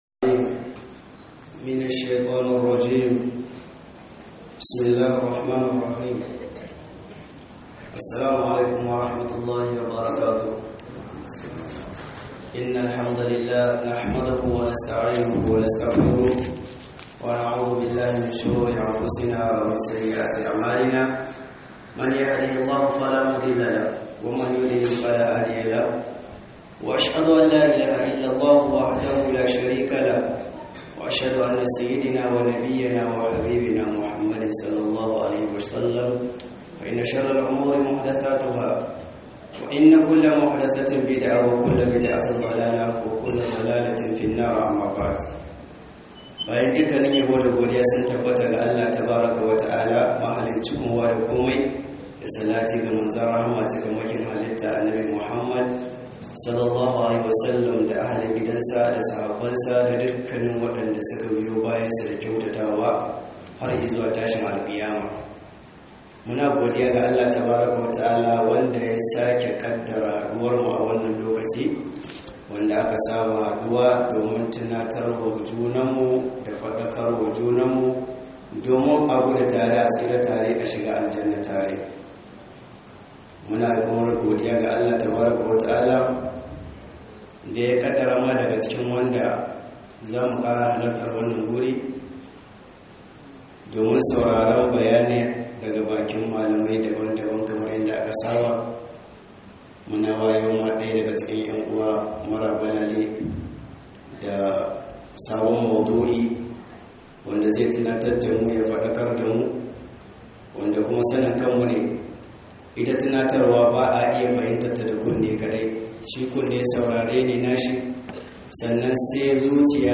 MUHADARA_MARKAZ_DA'AWA_02_2021_11_26_18'19'08' - MUHADARA